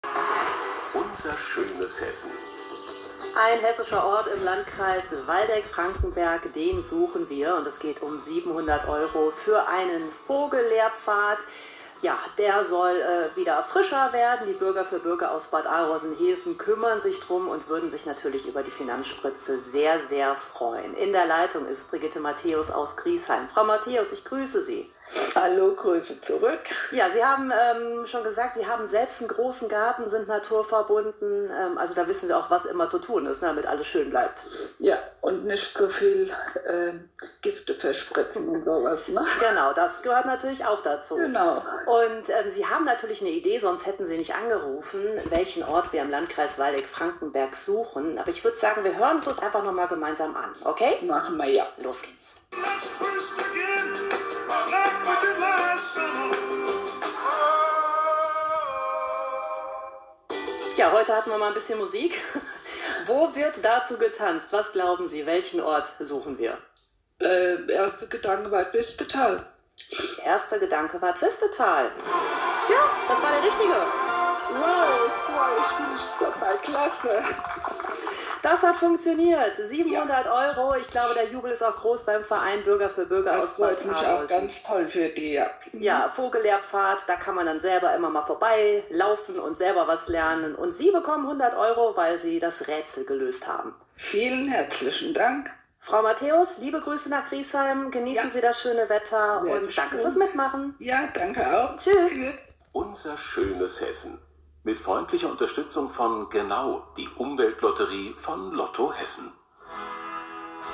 Am Dienstag, 22.04. wurde ich interviewt. Ich stellte unsere Planungen zur alten Vogelfahrt und künftigen Vogel- und Naturlehrpfad vor.